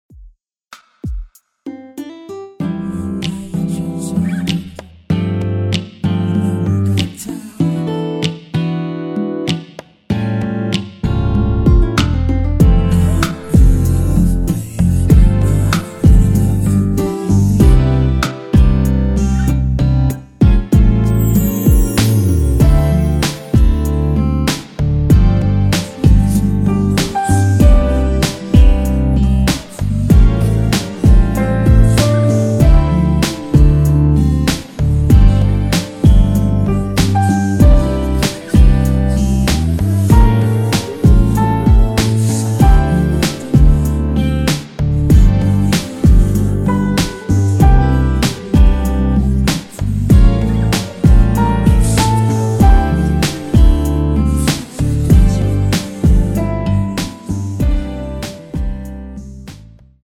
Dm
앞부분30초, 뒷부분30초씩 편집해서 올려 드리고 있습니다.